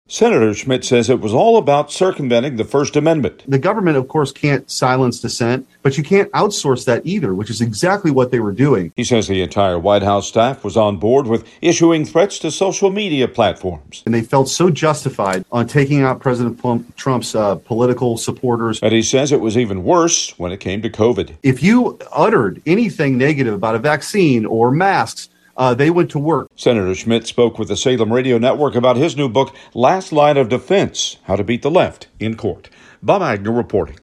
Senator Schmitt spoke with the Salem Radio Network about his new book Last Line Of Defense: How To Beat The Left In Court.